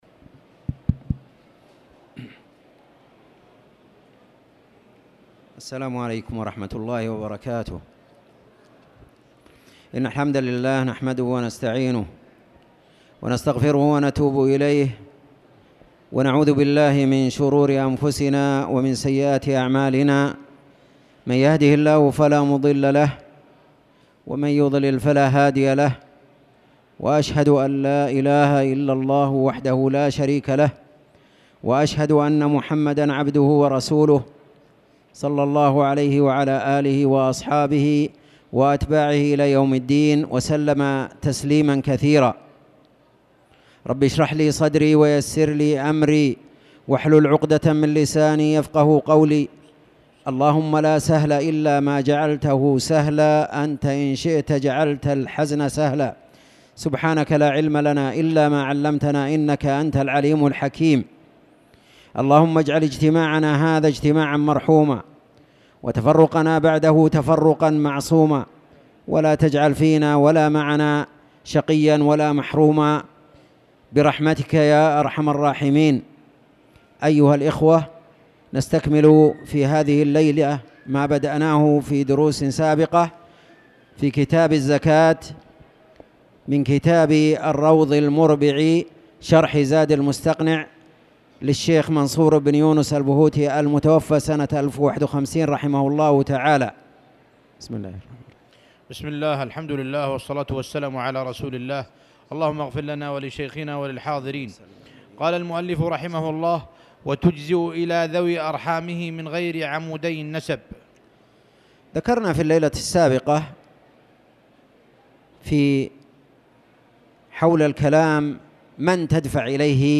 تاريخ النشر ٢٩ صفر ١٤٣٨ هـ المكان: المسجد الحرام الشيخ